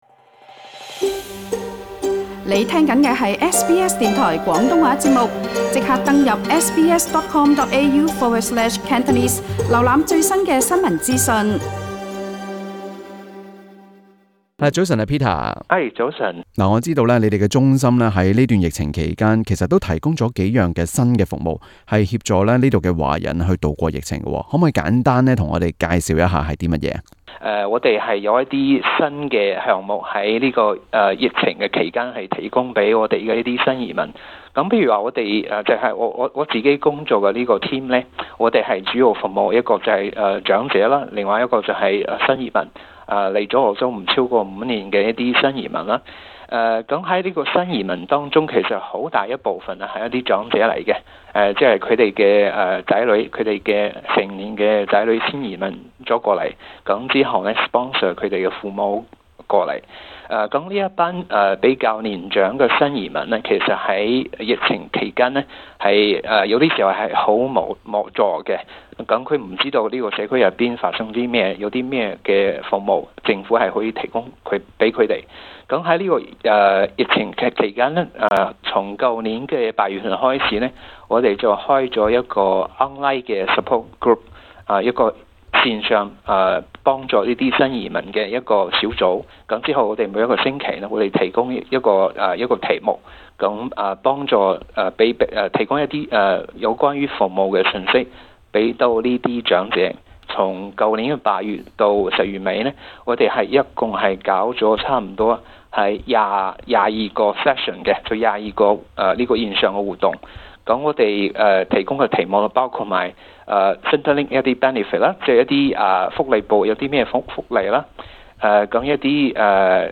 詳情請收聽這節【社區專訪】。